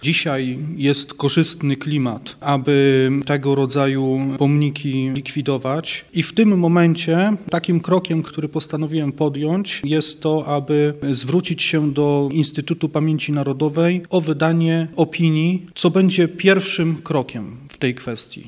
– Apel przyjmujemy ze zrozumieniem, na razie jednak musimy się zorientować, jaka jest klasyfikacja prawna tego pomnika – mówi przewodniczący nowogardzkiej Rady Miejskiej Piotr Słomski.